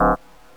some more puzzle sounds
wrong.wav